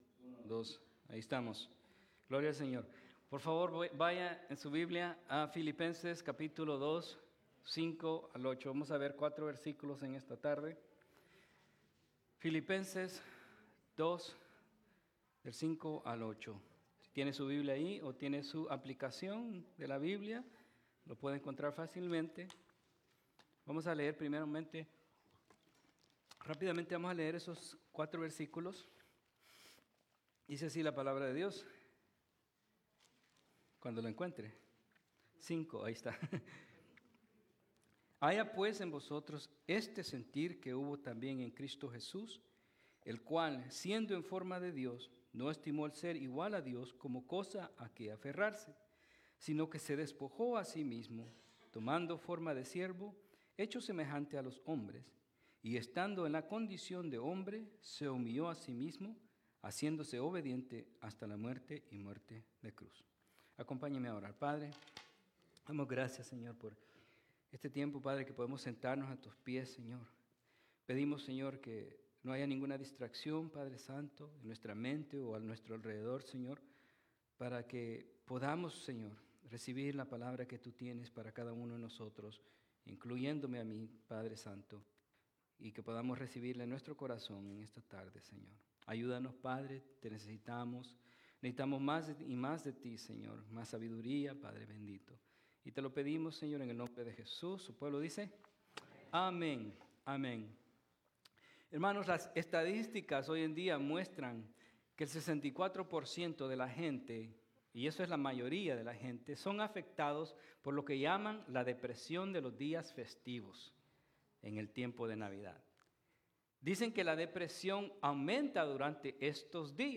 Escuche en línea o descargue Sermones 2024